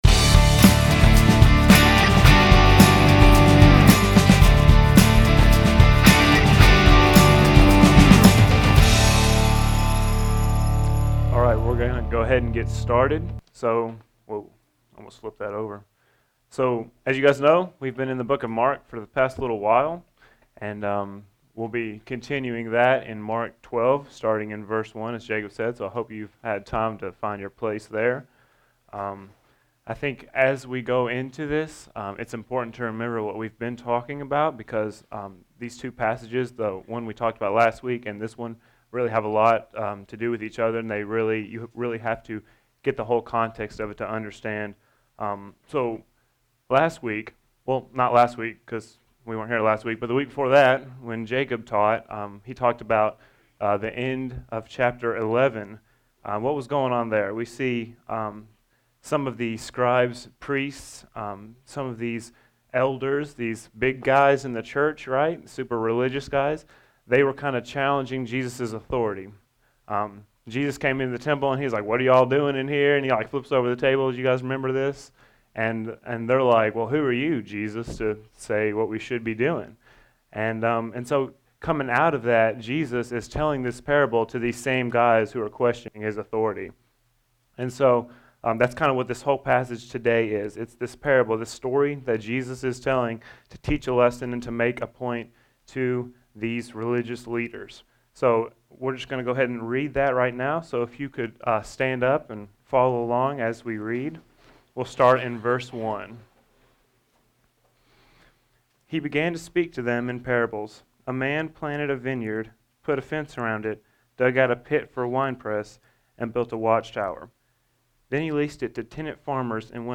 In Mark 12, Jesus is rejected. Even when Jesus is rejected by people, He still reigns as King over all. This sermon was originally preached to Henderson Student Ministry.